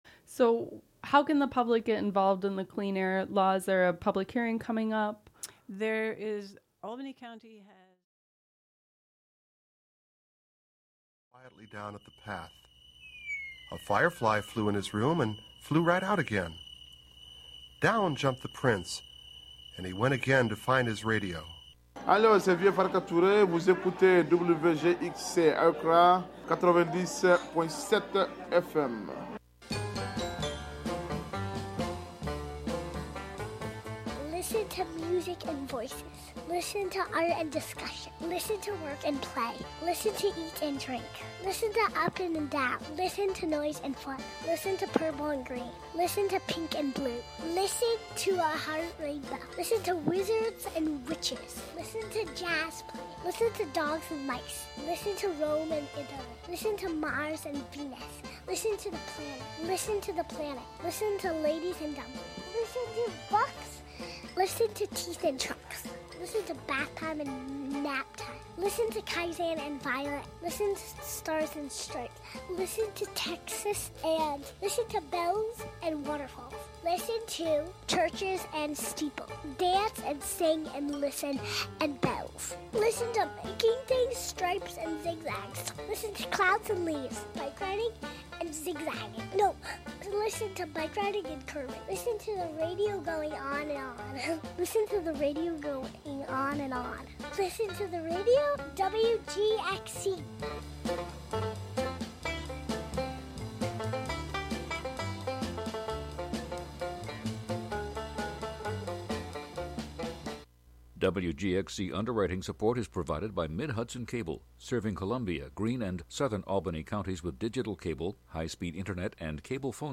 In this broadcast, a conversation